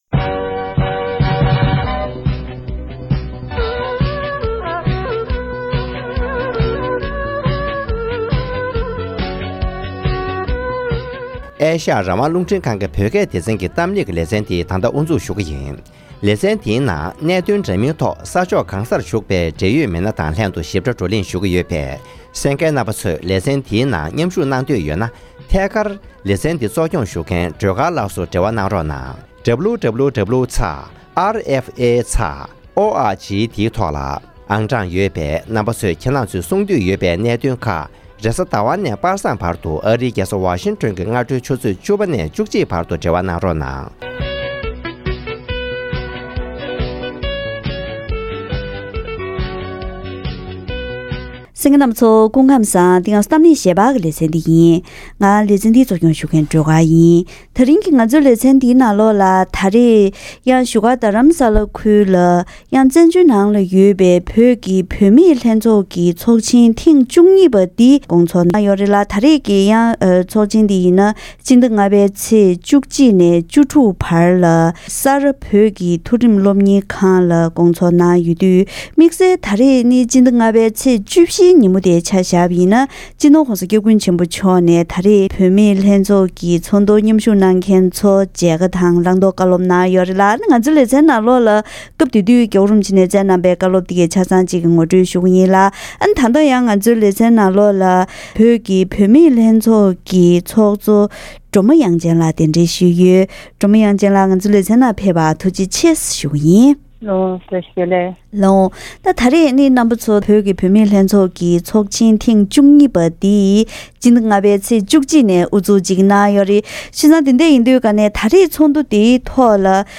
སྤྱི་ནོར་༧གོང་ས་༧སྐྱབས་མགོན་ཆེན་པོ་མཆོག་གིས་བུད་མེད་ལྷན་ཚོགས་ཀྱི་ཚོགས་ཆེན་ཐེངས་༡༢པར་ཕེབས་མཁན་ཚོར་མཇལ་ཁ་དང་བཀའ་སློབ་གནང་བ།